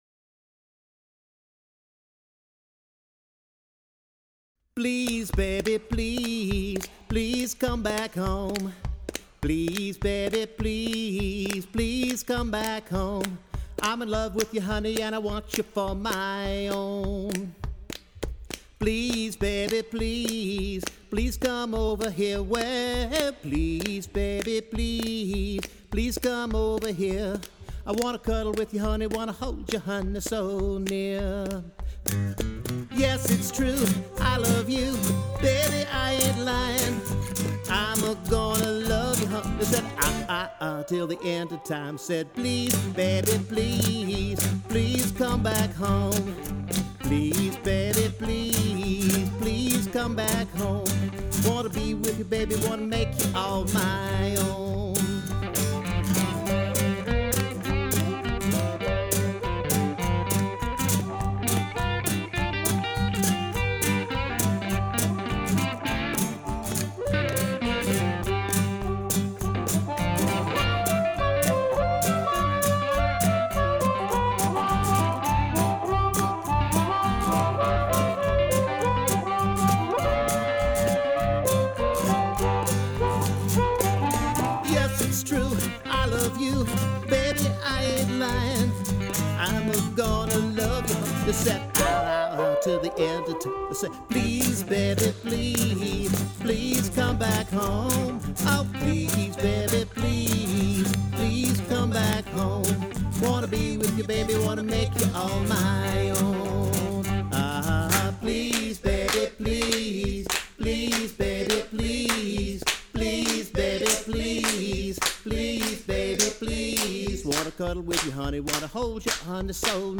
A fine and rollicking old
harp, background vocals and claps